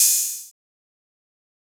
SouthSide Hi-Hat (5).wav